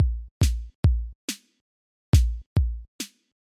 鼓点140没有踢腿
标签： 140 bpm Electronic Loops Drum Loops 590.80 KB wav Key : Unknown
声道立体声